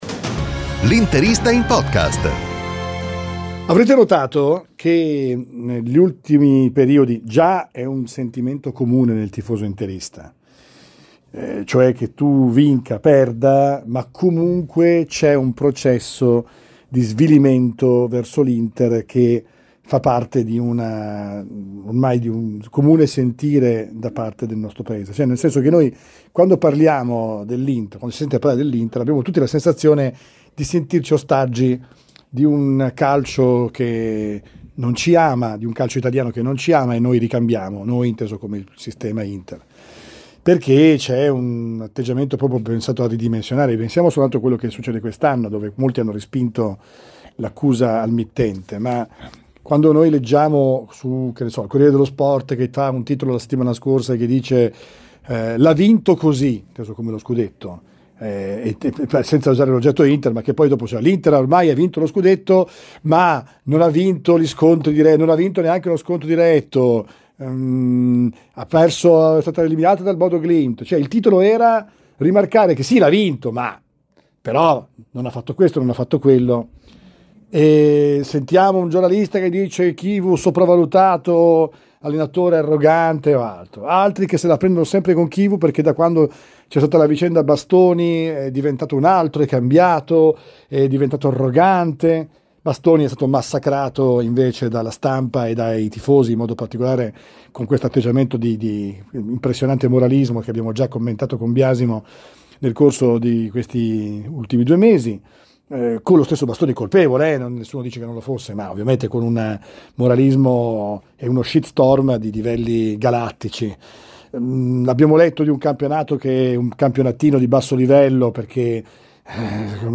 Analizziamo le recenti critiche rivolte all'allenatore Cristian Chivu e al difensore Alessandro Bastoni, evidenziando un clima di moralismo e di svalutazione che sembra permeare il discorso pubblico. Con l'intervento di esperti e tifosi, discutiamo delle ingiustizie mediatiche e delle aspettative irrealistiche che gravano sulla squadra, in un contesto in cui il campionato viene spesso sminuito.